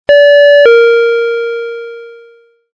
Kostenlose Klingeltöne digital bell